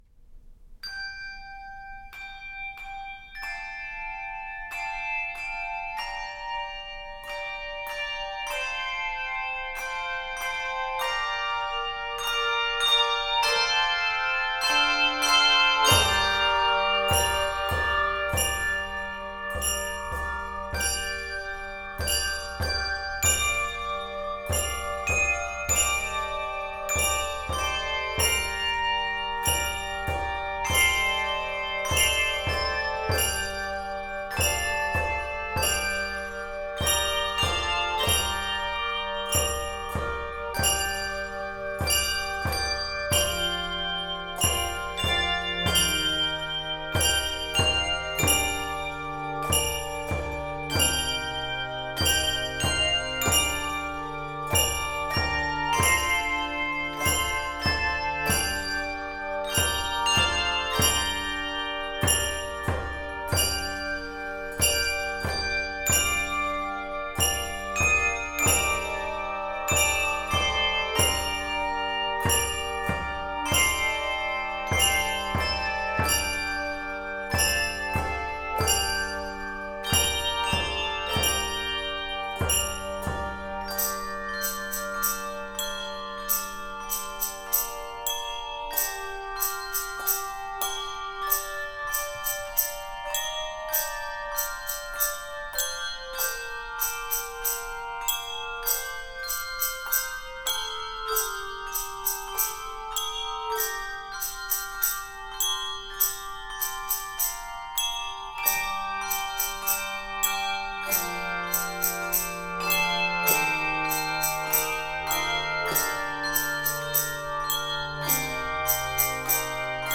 Key of g minor.